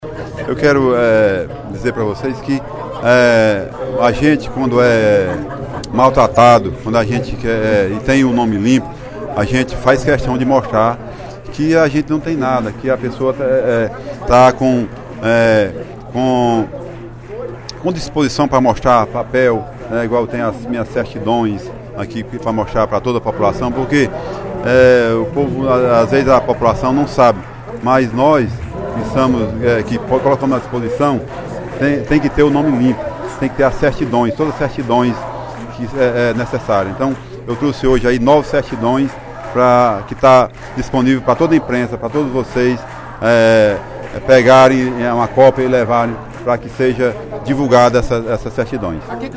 O deputado Naumi Amorim (PMB) manifestou, no primeiro expediente da sessão plenária da Assembleia Legislativa desta terça-feira (19/07), a sua indignação sobre matéria veiculada pela revista IstoÉ, na semana passada, que o teria acusado de apresentar uma folha corrida com 148 processos, sendo dois por homicídio.